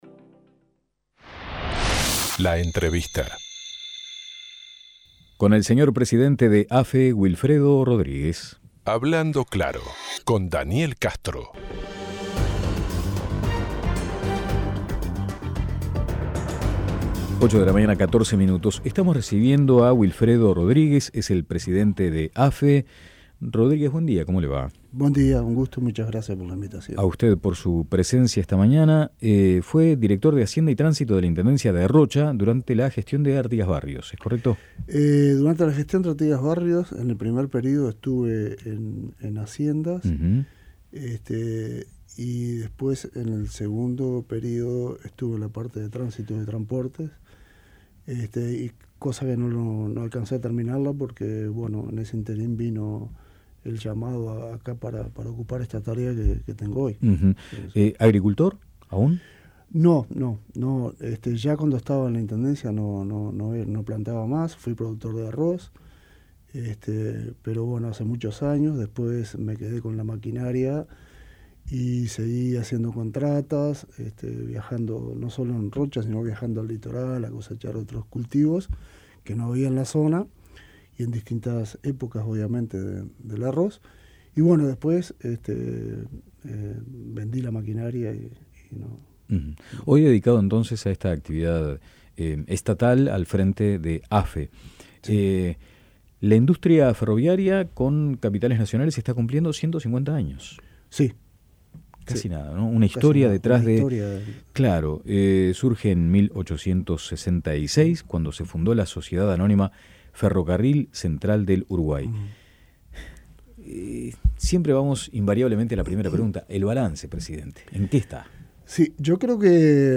Con motivo de los 150 años de la Asociación de Ferrocarriles del Estado (AFE), conversamos en La Mañana de El Espectador con el presidente de la institución, Wilfredo Rodríguez sobre la relación entre el ferrocarril y la futura planta de UPM.
Escuche la entrevista de La Mañana: